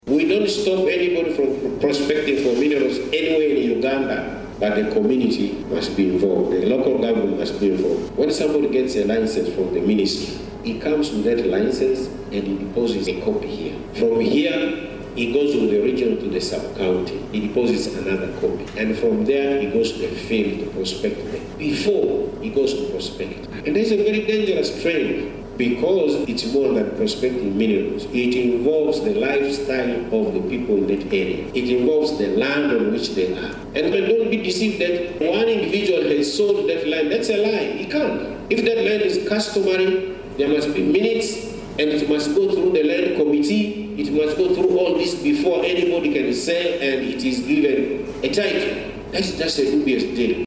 Hon. Obiga Kania, the State Minister for Lands, Housing and Urban Development
Obiga Kania State Minister Urban Development.mp3